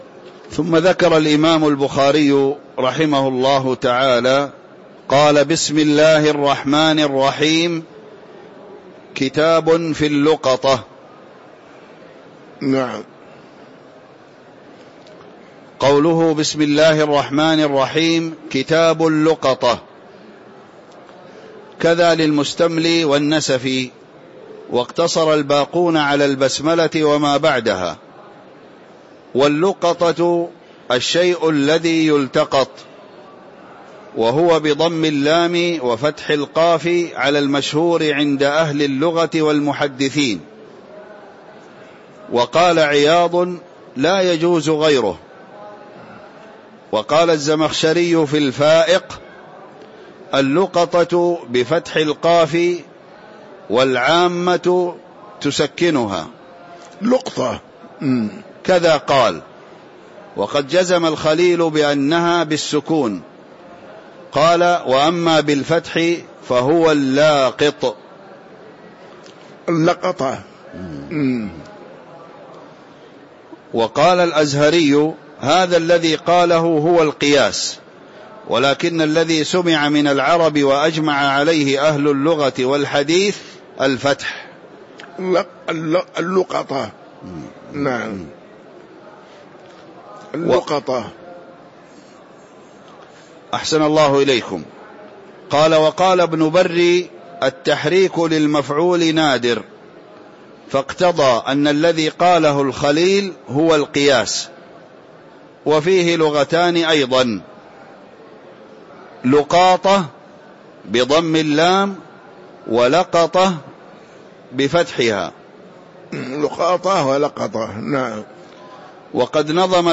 تاريخ النشر ١٨ رجب ١٤٤٥ هـ المكان: المسجد النبوي الشيخ